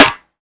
SNARE.87.NEPT.wav